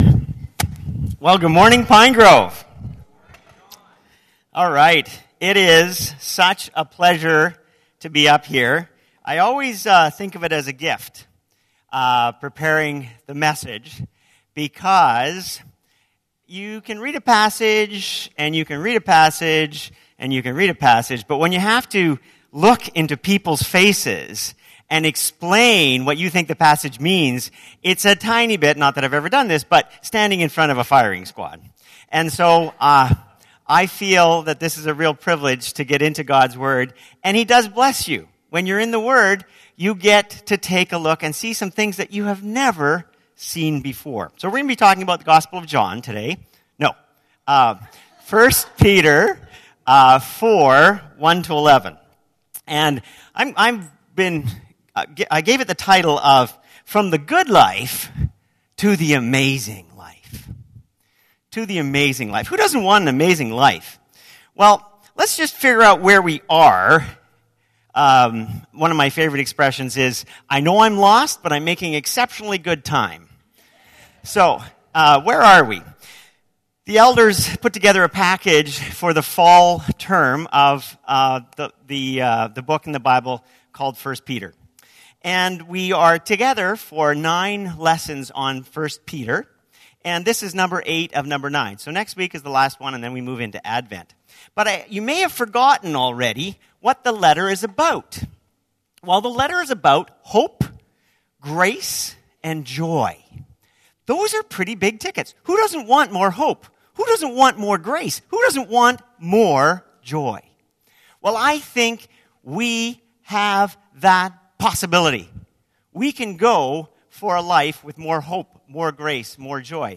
This sermon is based on 1 Pet 4:1-11.